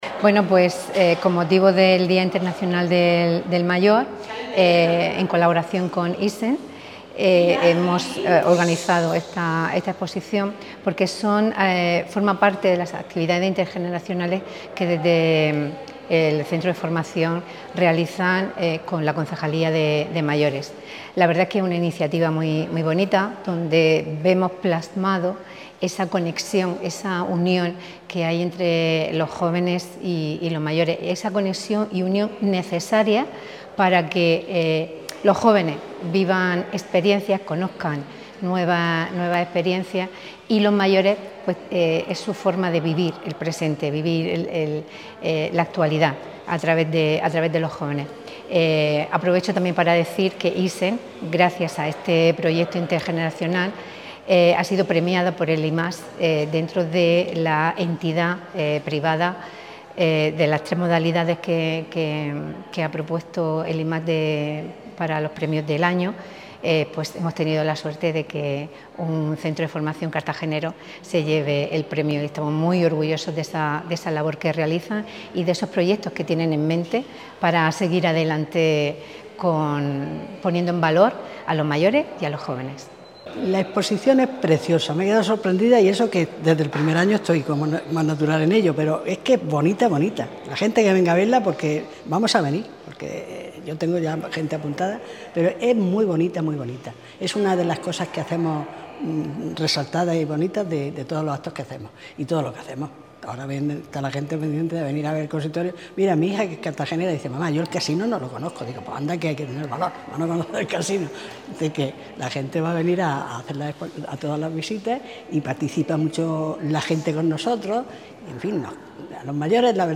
La inauguración de la muestra forma parte de los actos de conmemoración del Día de las Personas Mayores que organiza esta semana el Ayuntamiento de Cartagena.